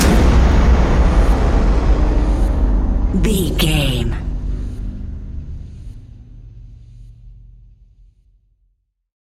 Fast paced
In-crescendo
Thriller
Ionian/Major
industrial
dark ambient
EBM
drone
synths
Krautrock